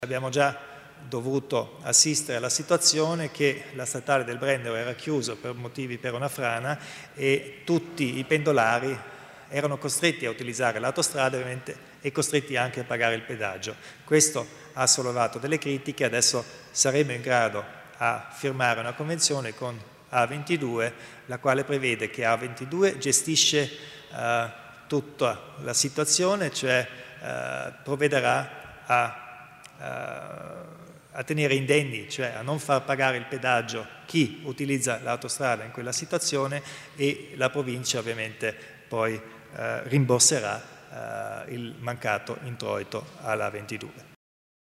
Il Presidente Kompatscher illustra le novità riguardanti l'A22